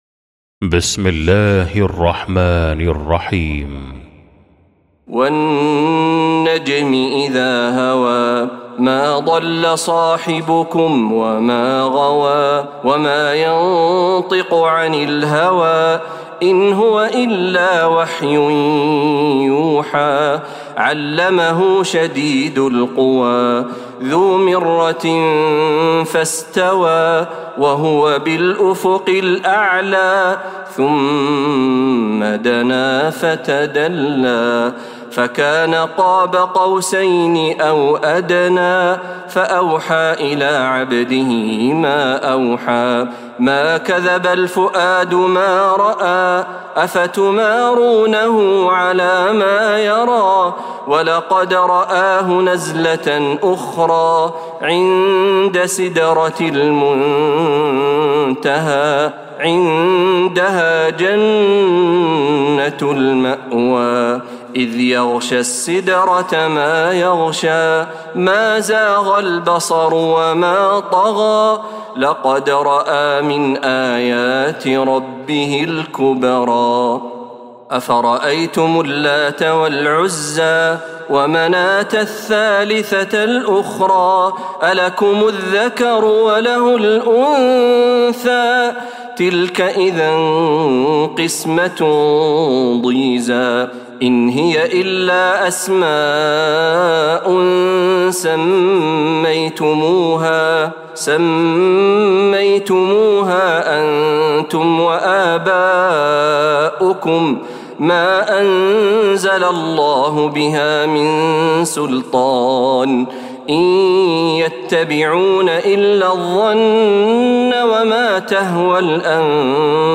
سورة النجم Surah An-Najm > مصحف تراويح الحرم النبوي عام 1446هـ > المصحف - تلاوات الحرمين